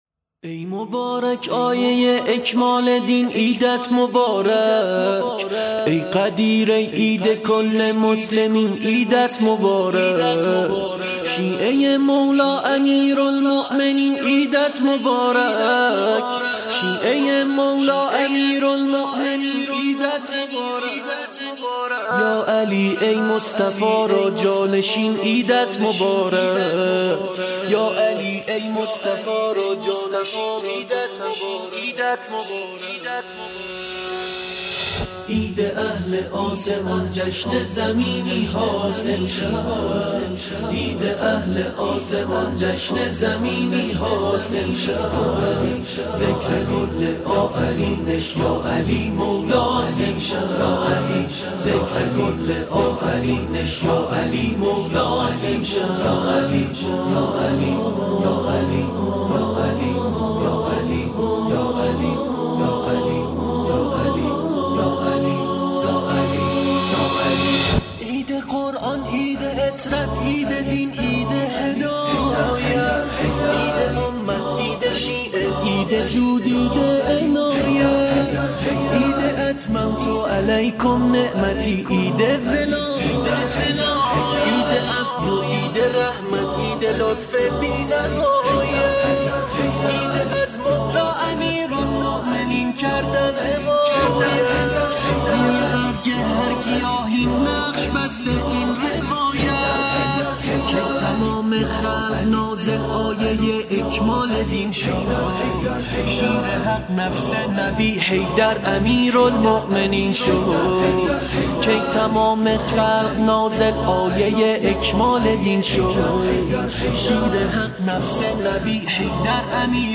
مولودی زیبا و دلنشین